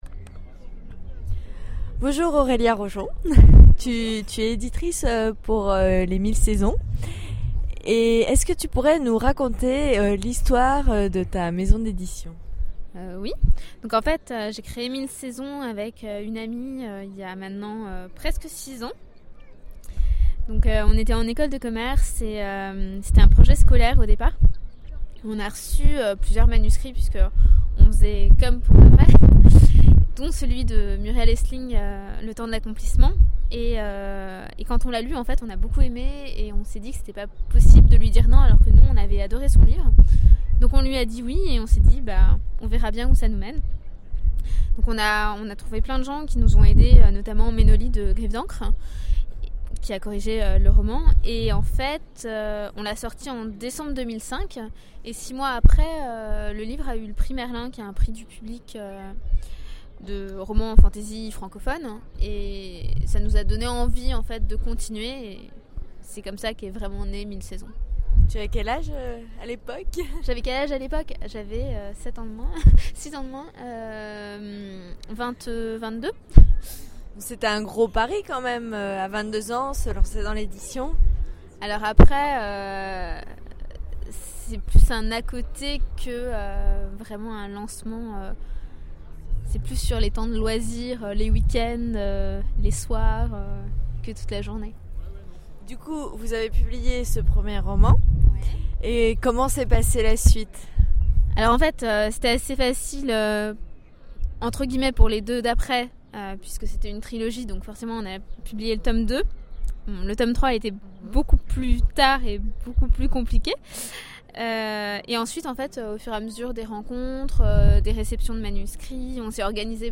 ITW Mille Saisons